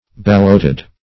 Balloted synonyms, pronunciation, spelling and more from Free Dictionary.